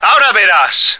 flak_m/sounds/male2/est/M2yousuck.ogg at ac4c53b3efc011c6eda803d9c1f26cd622afffce